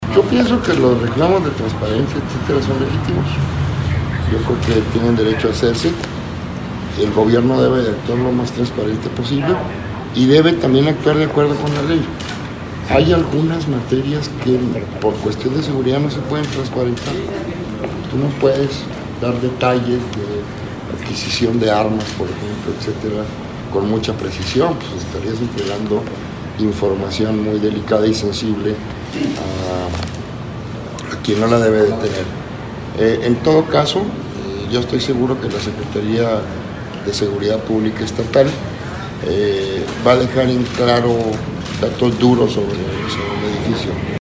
Audio. Secretario General de Gobierno, César Jáuregui Moreno.
Así mencionó durante una entrevista a medios de comunicación.